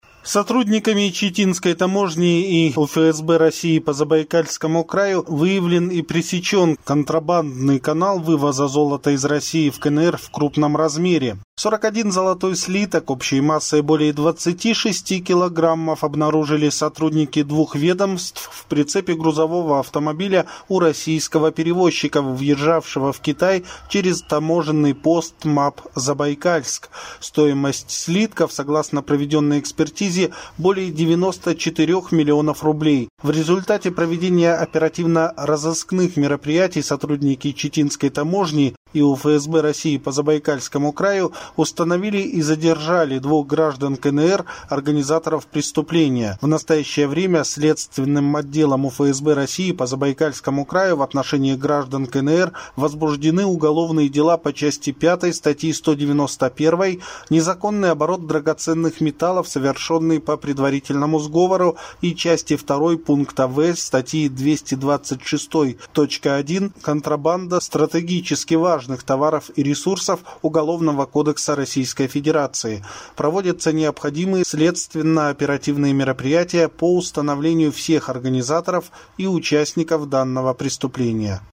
Диктор новости